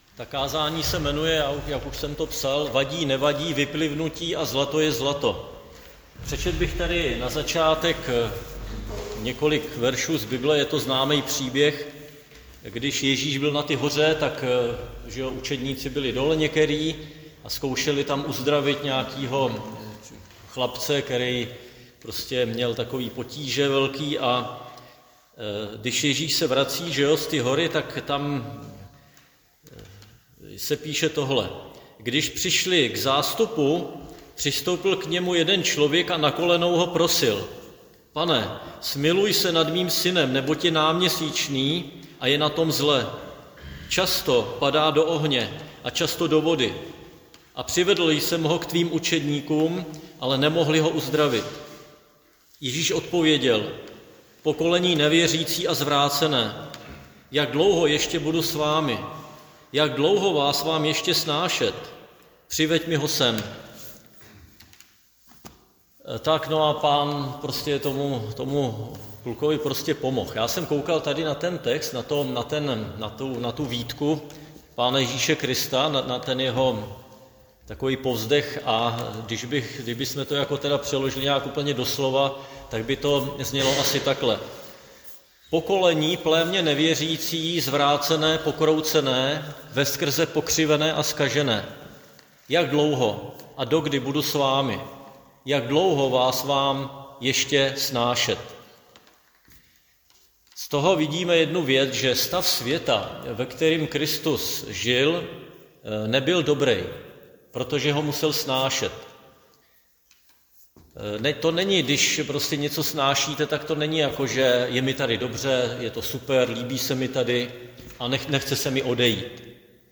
Křesťanské společenství Jičín - Kázání 2.3.2025